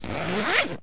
foley_zip_zipper_long_05.wav